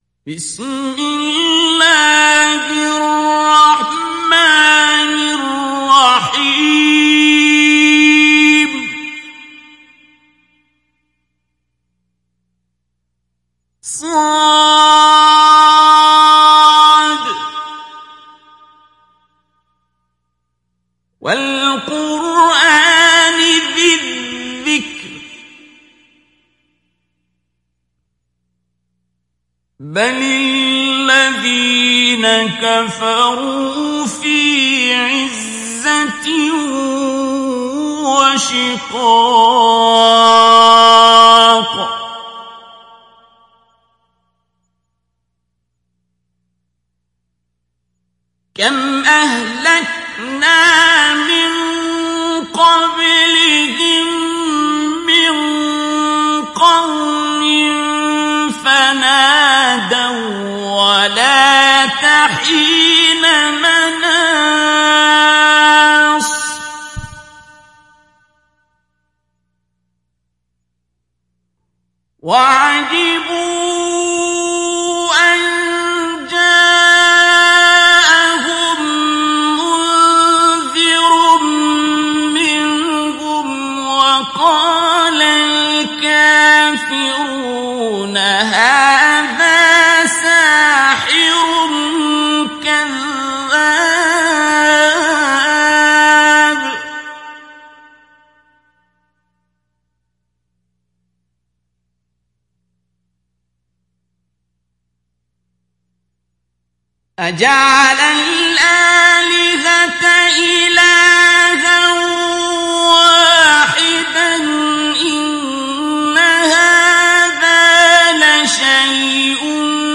ডাউনলোড সূরা সদ Abdul Basit Abd Alsamad Mujawwad